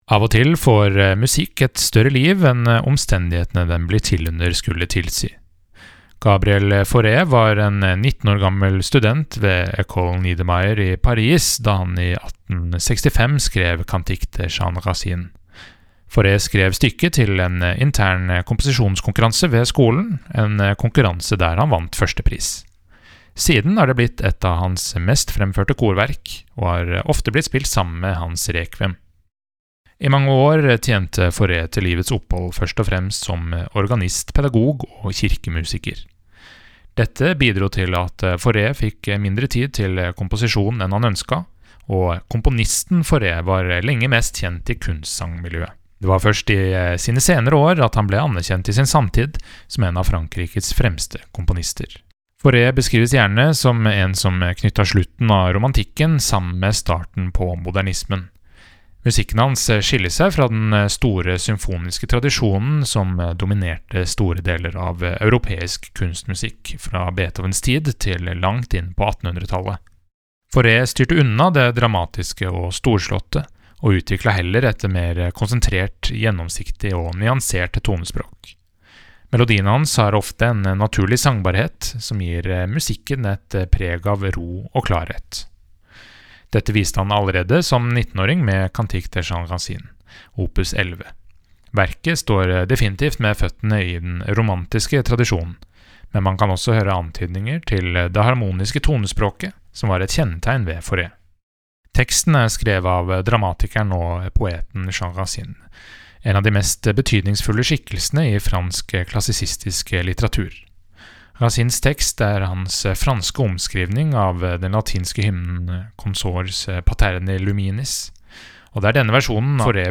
VERKOMTALE: Gabriel Faurés Cantique de Jean Racine